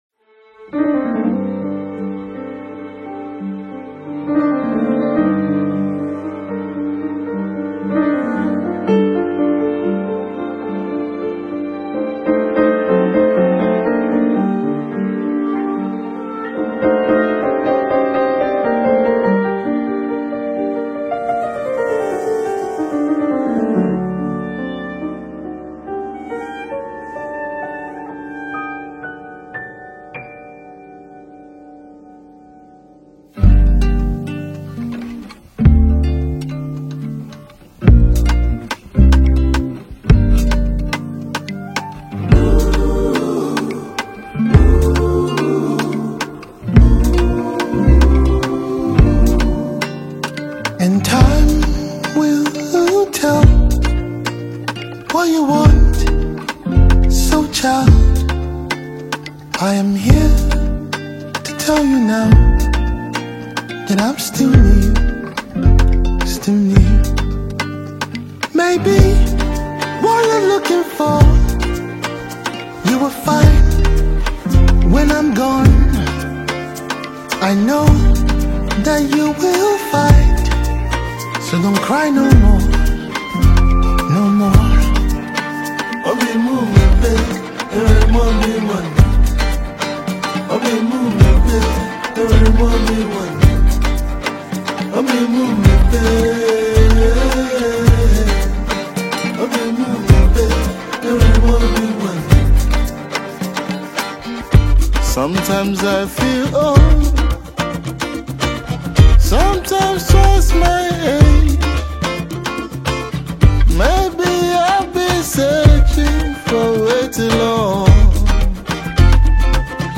Talented Nigerian duo singer and songwriter